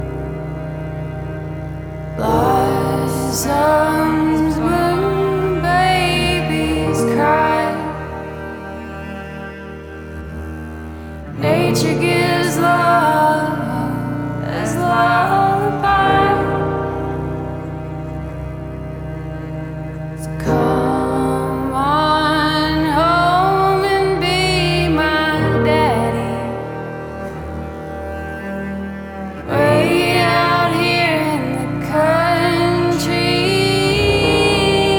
Жанр: Альтернатива
# Alternative